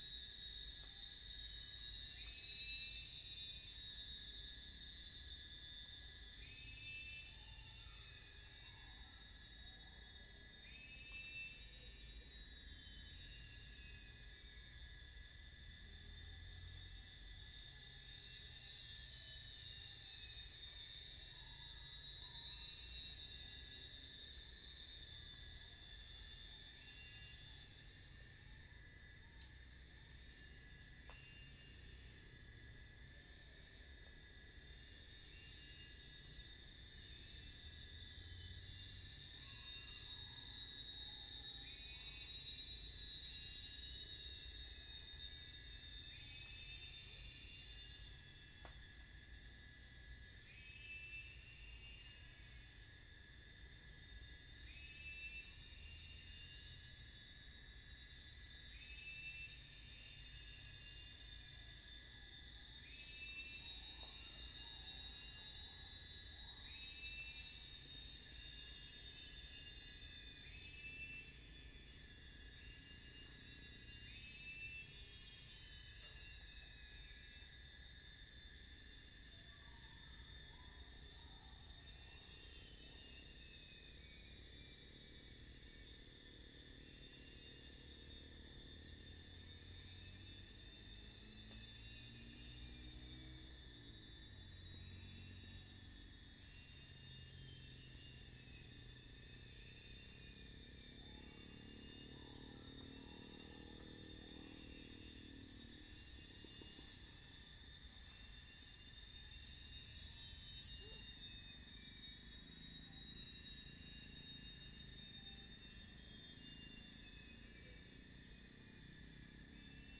40228 | Gallus 40224 | 0 - unknown bird 40230 | Pycnonotus 40229 | 0 - unknown bird 40225 | Dicaeum 40227 | Dicaeum 40226 | Dicaeum